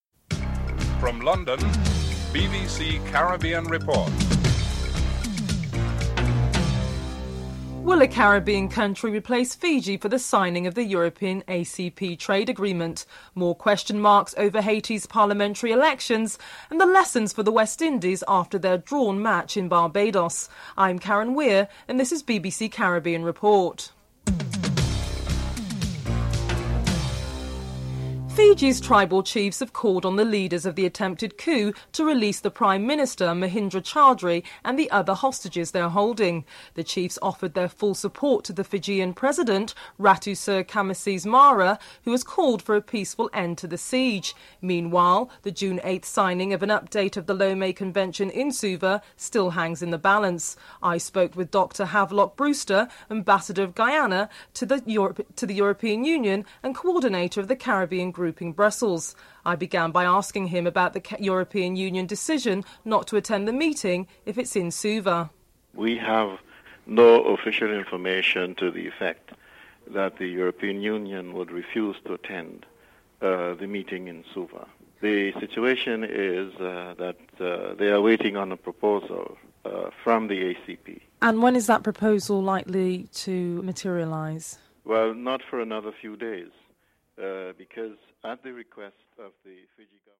1. Headlines: (00:00-00:26)